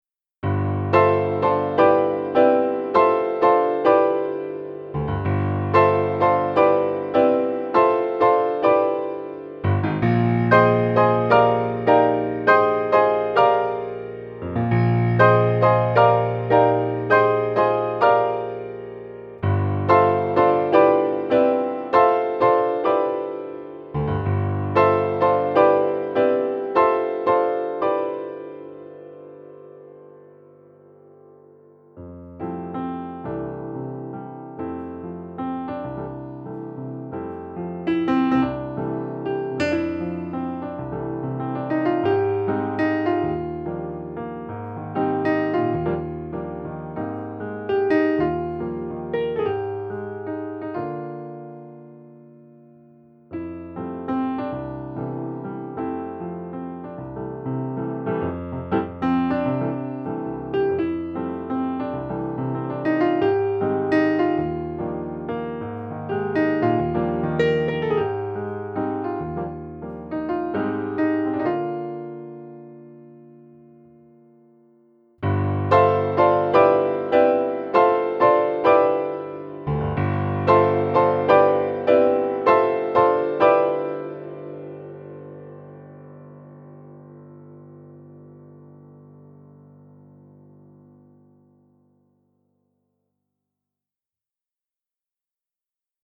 本物のアコースティックサウンドボードを備えたデジタルミニグランドピアノ
USA Grand PLT